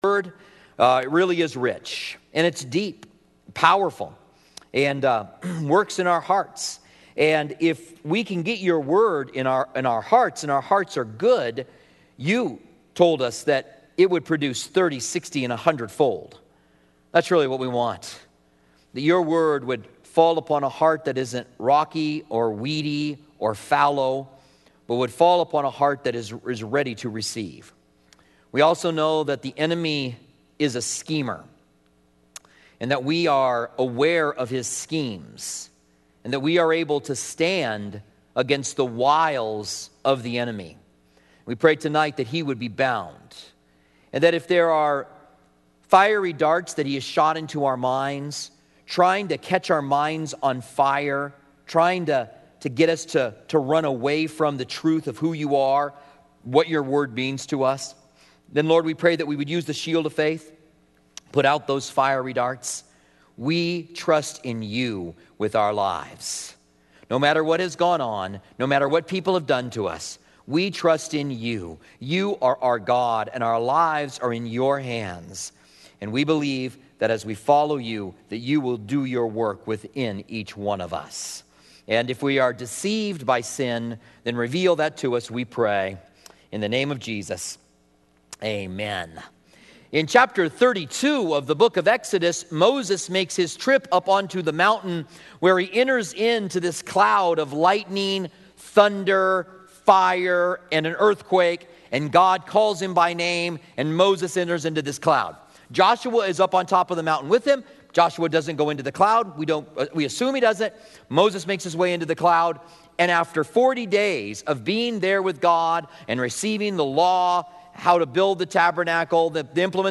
Commentary on Exodus